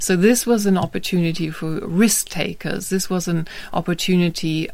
Now, let’s state a general rule: broadly speaking, the phoneme /k/ is very frequently dropped when it appears in the middle of the cluster /skt/.